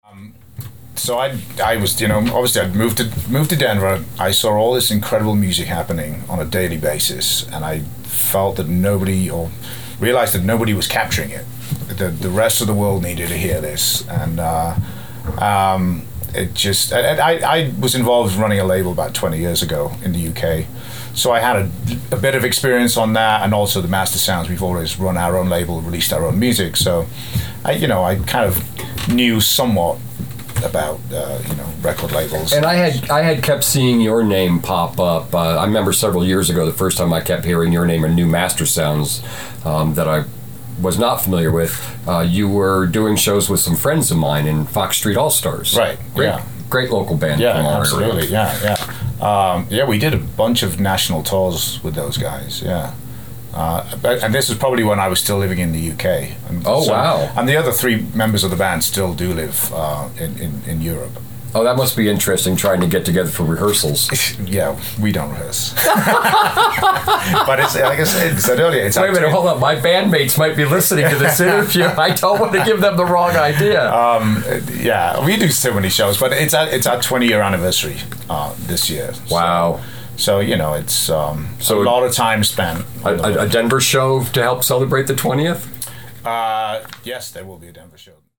Here’s an excerpt from our conversation.